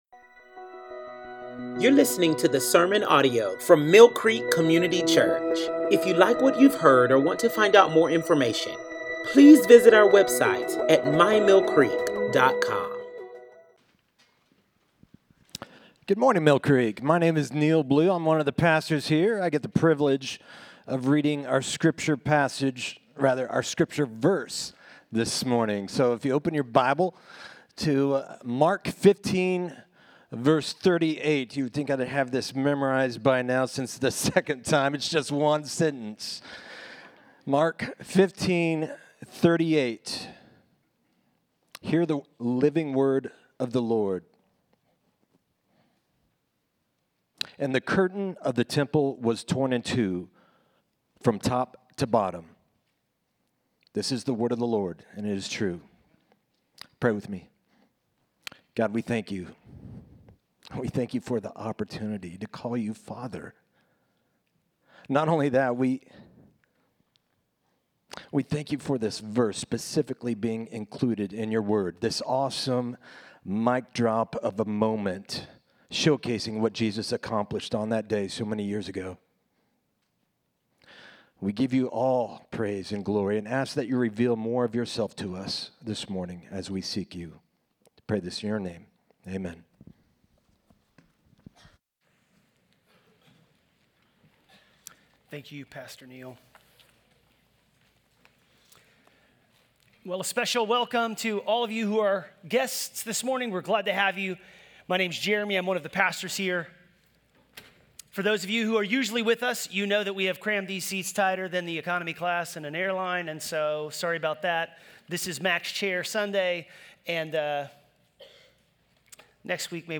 Mill Creek Sunday Morning Sermons
Sermons from Mill Creek Community Church: Shawnee, KS